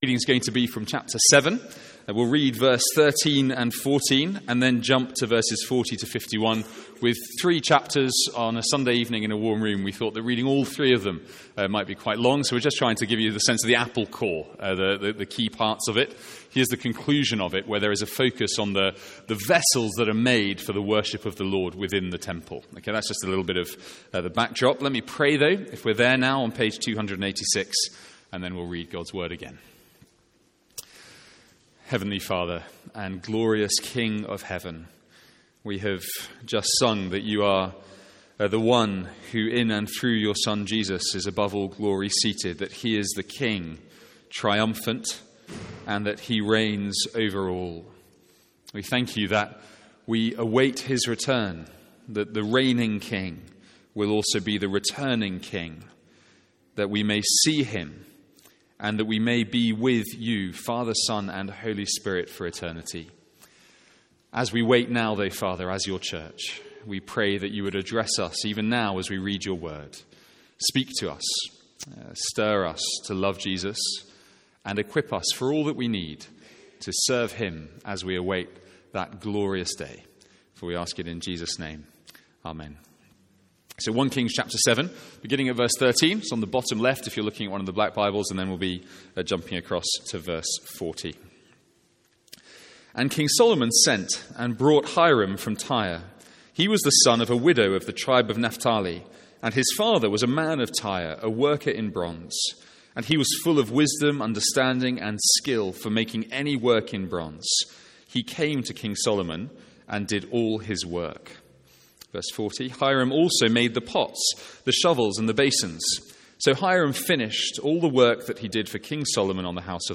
Sermons | St Andrews Free Church
From our evening series in 1 Kings.